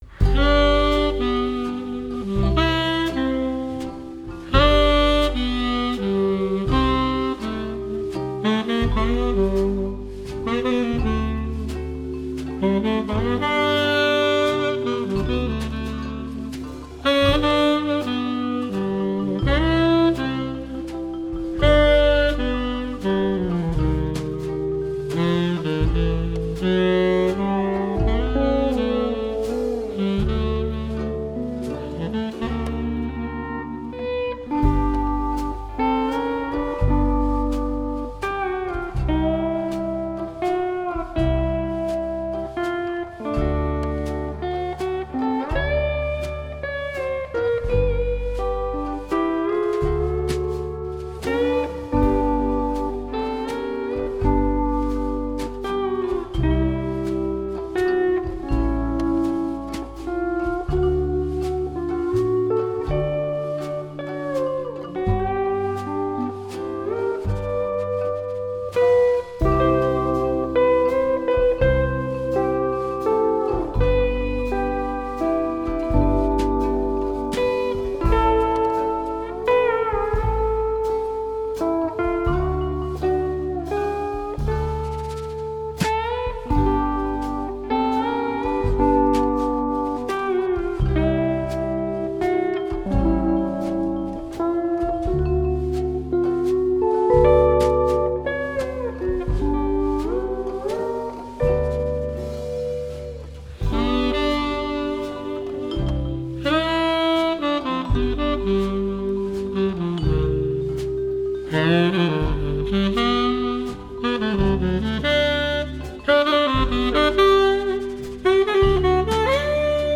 Sonorité métissée, jazzy et improvisée